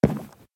Sound / Minecraft / step / wood3.ogg
wood3.ogg